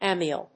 エイミエル； アミエル